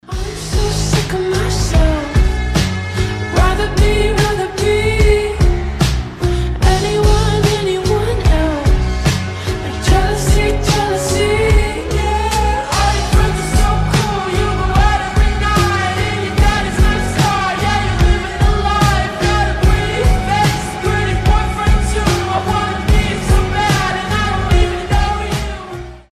• Качество: 320, Stereo
женский голос
чувственные
кайфовые
ремиксы